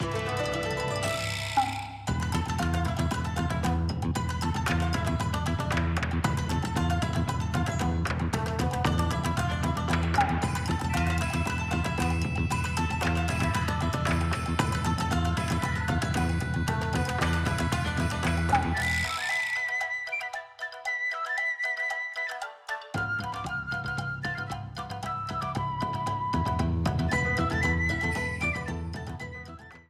A blue streamer theme
Ripped from the game
clipped to 30 seconds and applied fade-out